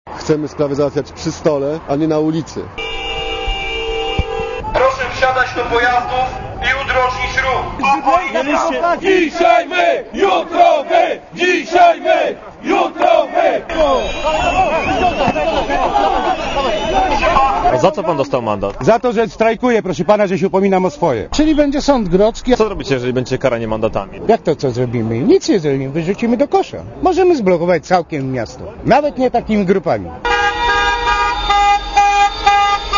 Zablokowane centrum Warszawy i nieustający dźwięk wyjących klaksonów. Tak wyglądał kolejny już protest taksówkarzy przeciwko obowiązkowi montowania kas fiskalnych.
Komentarz audio (132Kb)